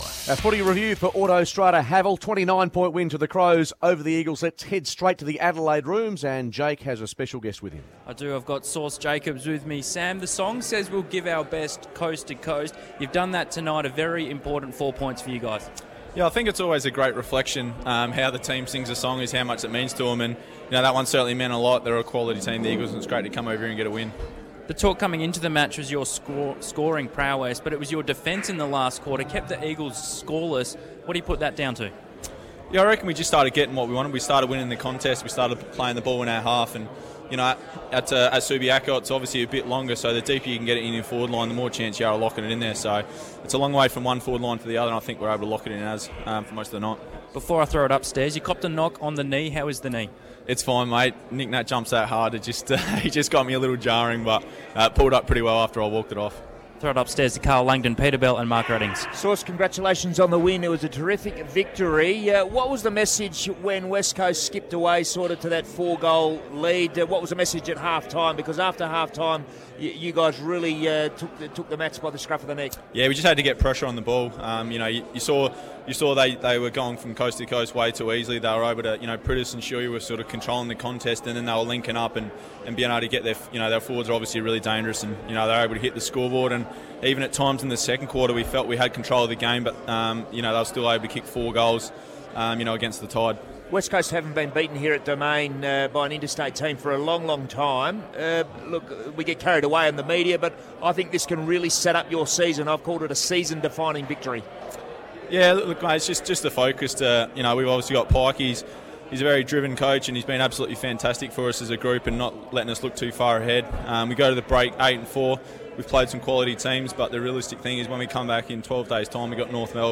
Sam Jacobs on 6PR